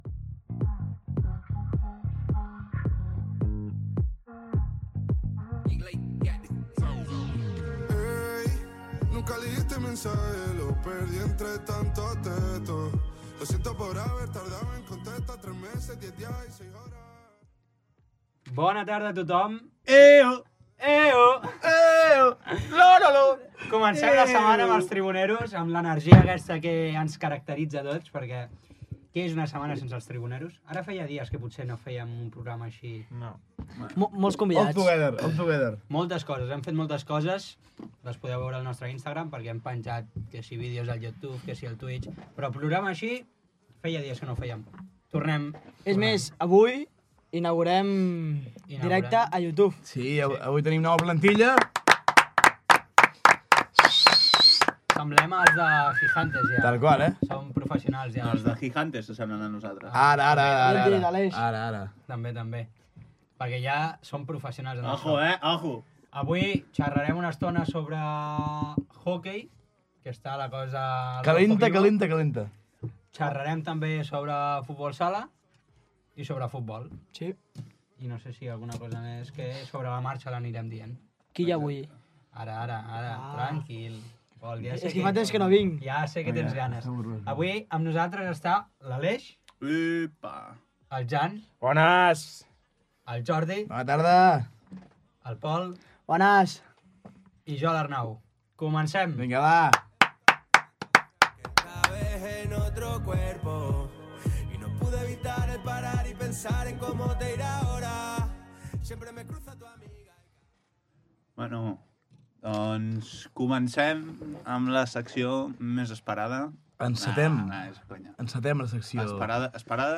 Sintonia, presentació, sumari del programa, equip, comentari sobre hoquei patins i l'últim partit del Club Esportiu Noia Freixenet
Esportiu